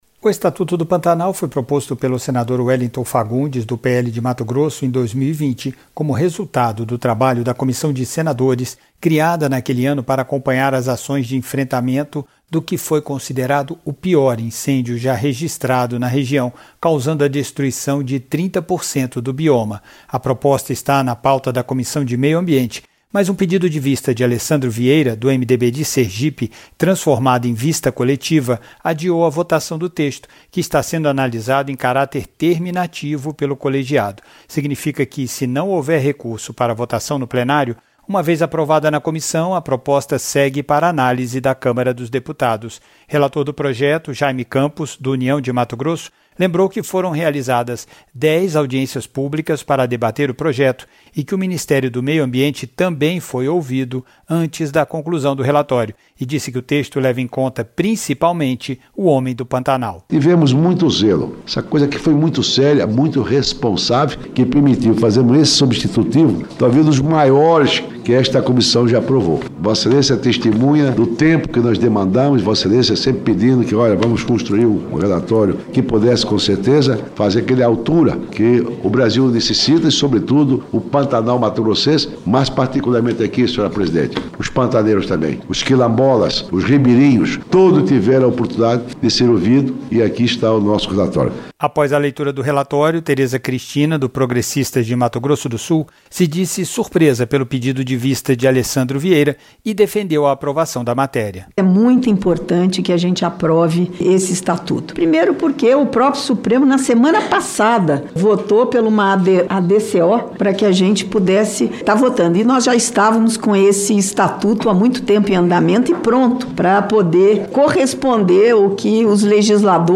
O relator, Jayme Campos (União-MT), disse que proposta leva em conta sobretudo os pantaneiros.